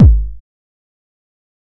nightcore-kick.wav